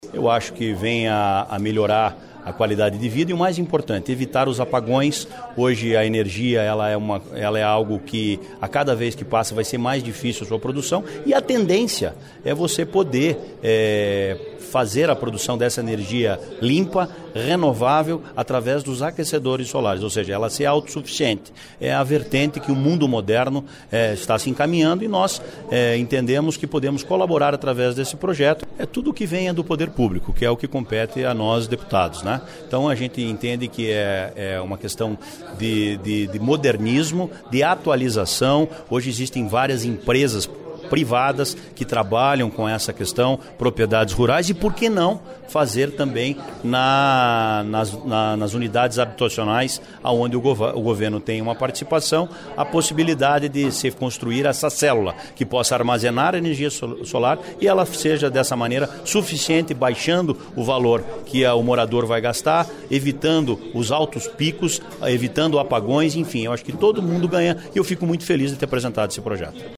Ouça a entrevista com o autor da proposta, o deputado Anibelli neto (PMDB).